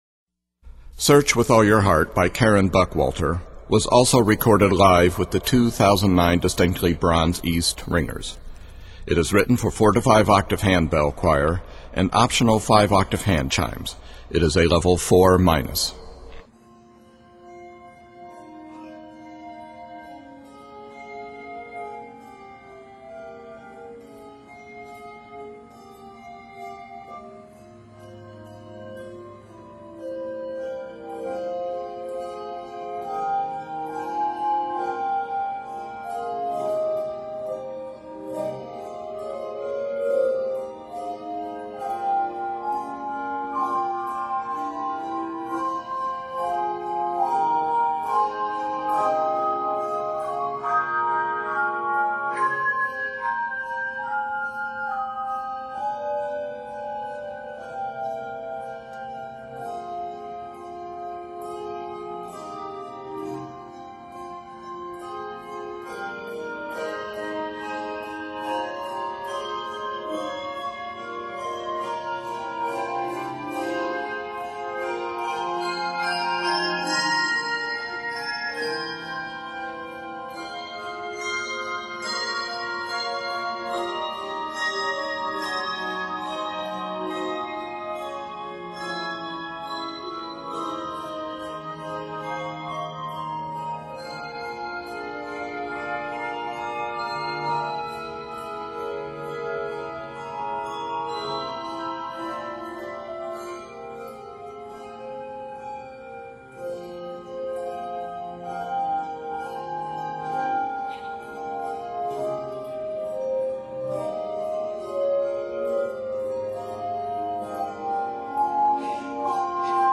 N/A Octaves: 4-5 Level